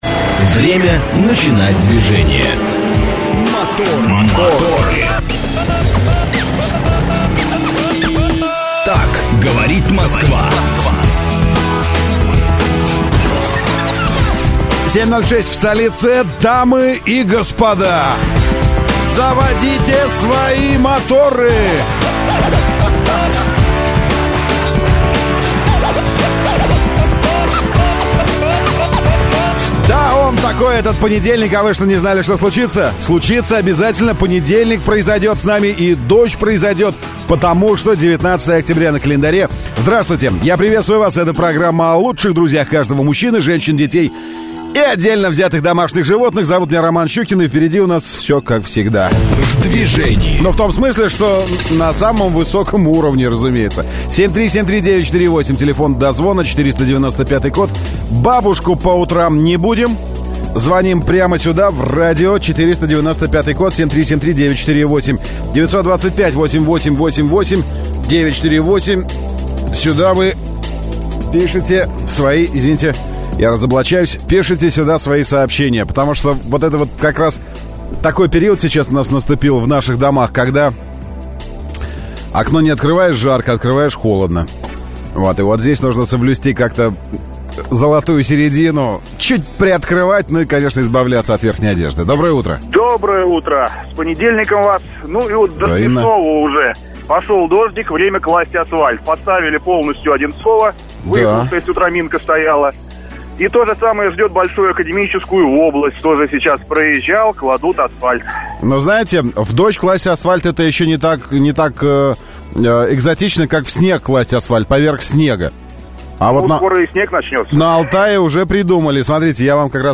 Утренняя программа для водителей и не только. Ведущие рассказывают о последних новостях автомобильного мира, проводят со слушателями интерактивные «краш-тесты» между популярными моделями одного класса, делятся впечатлениями от очередного тест-драйва.